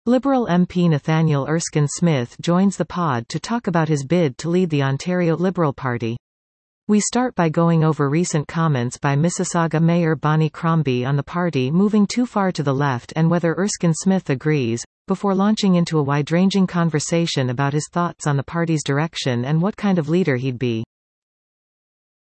Liberal MP Nathaniel Erskine-Smith joins the pod to talk about his bid to lead the Ontario Liberal Party.